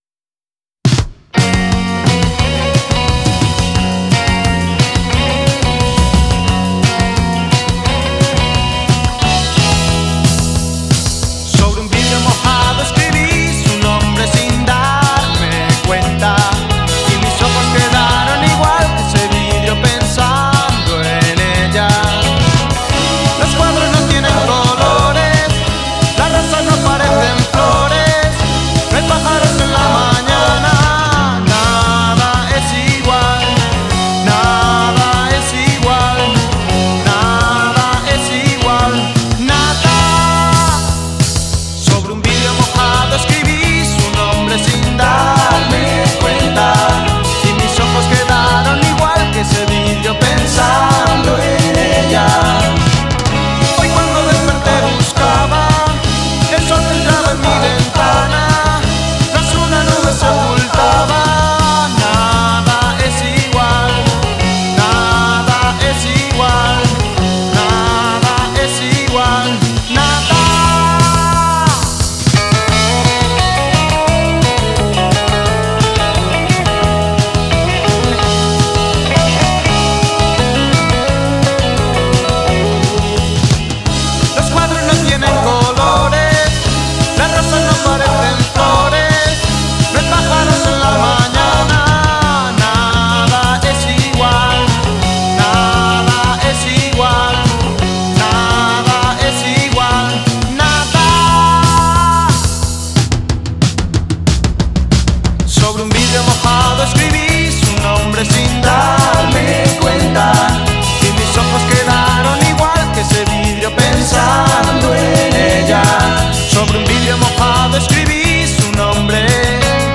vibes a bit more of 1980s poprock sound